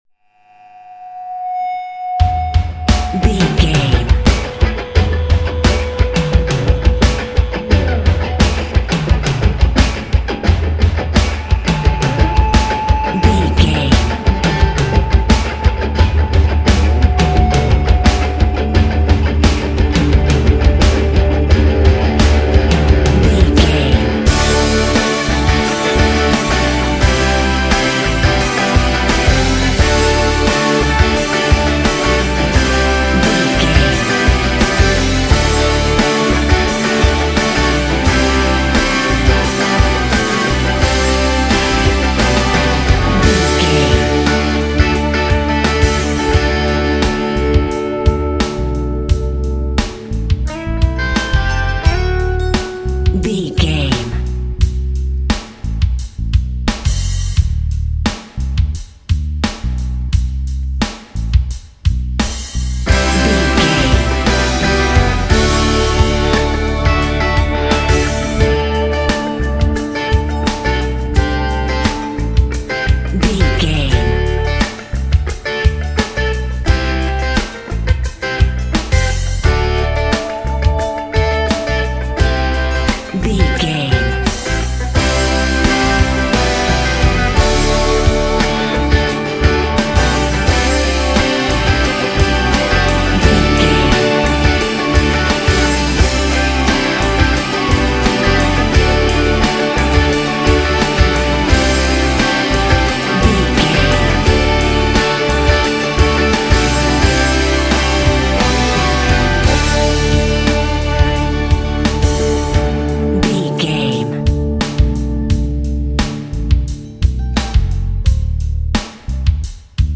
Epic / Action
Fast paced
Mixolydian
heavy metal
heavy rock
blues rock
distortion
hard rock
Instrumental rock
drums
bass guitar
electric guitar
piano
hammond organ